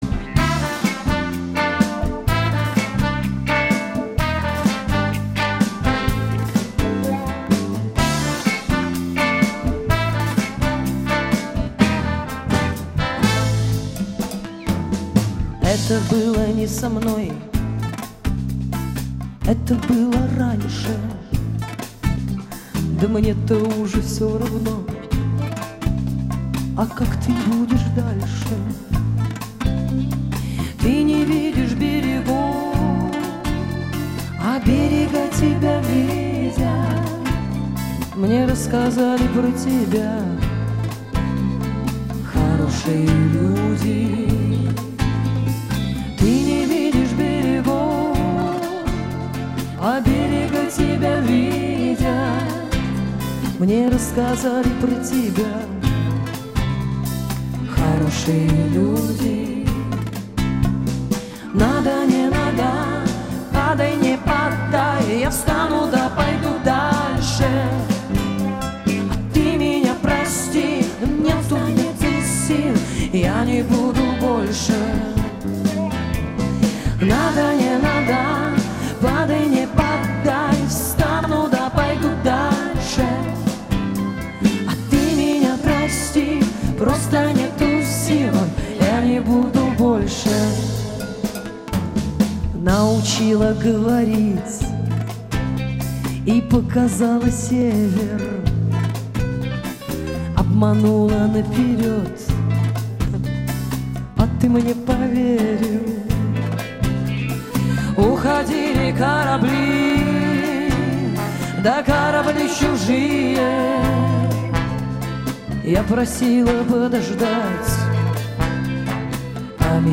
Фолк рок